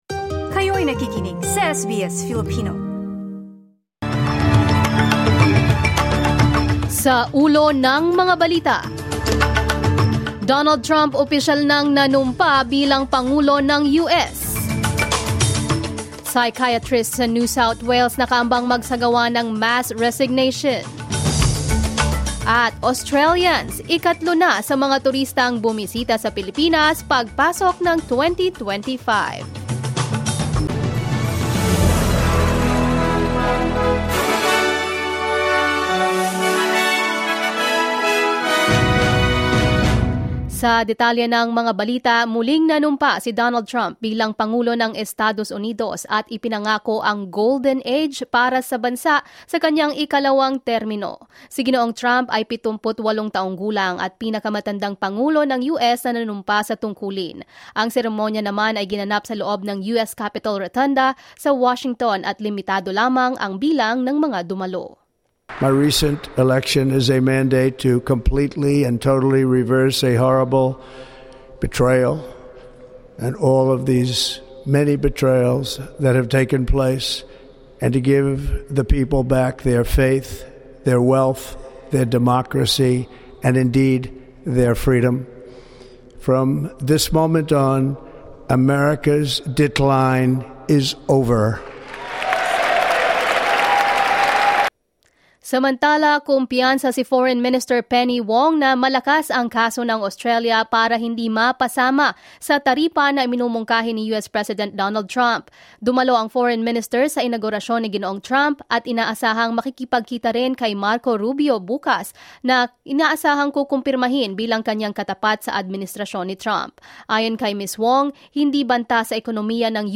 SBS news in Filipino, Tuesday 21 January 2025